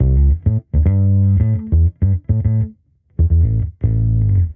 Index of /musicradar/sampled-funk-soul-samples/105bpm/Bass
SSF_PBassProc1_105A.wav